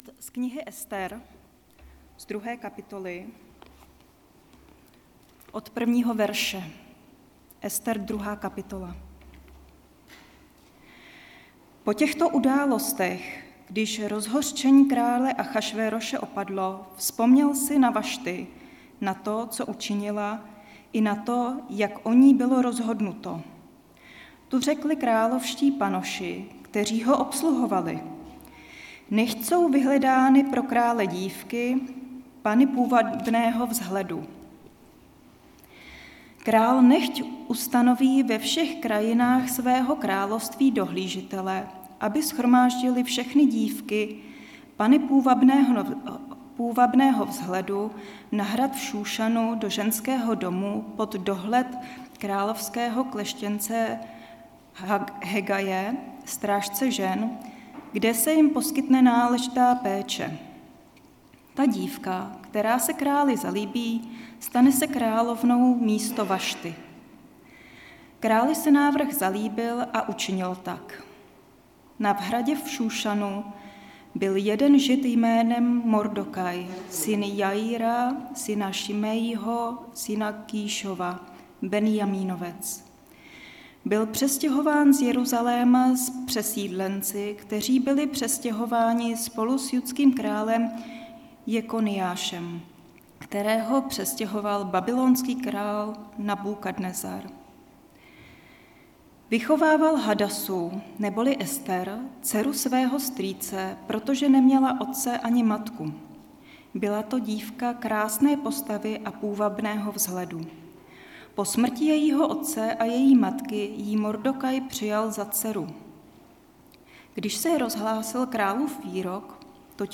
2. díl ze série kázání Ester (2,1-23)
Kategorie: Nedělní bohoslužby